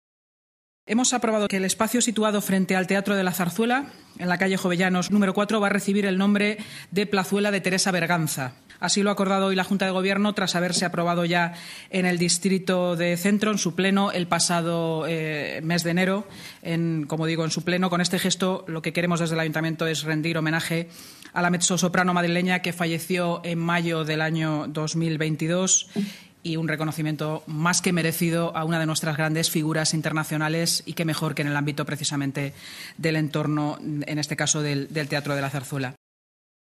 Así lo ha anunciado esta mañana la portavoz municipal, Inmaculada Sanz: